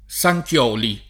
[ S a j k L0 li ]